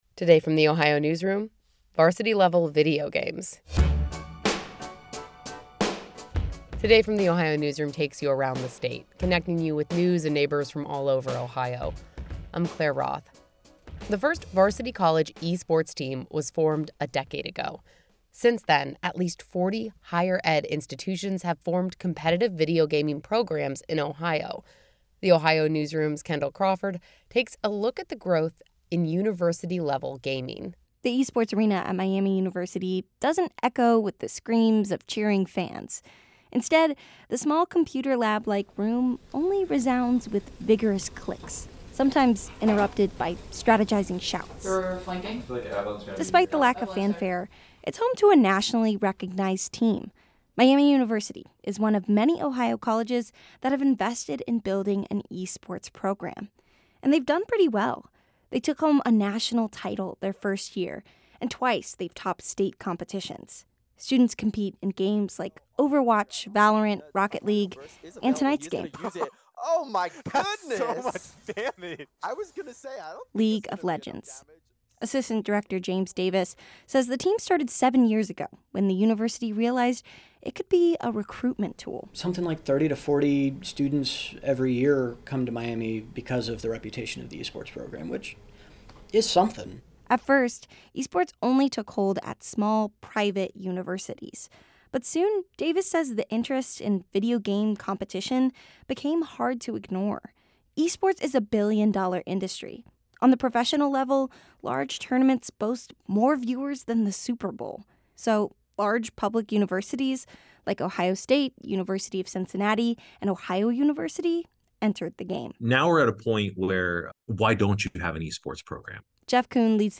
The esports arena at Miami University doesn’t echo with the screams of cheering fans. Instead, the small computer lab-like room only resounds with vigorous clicks, occasionally interrupted by strategizing shouts.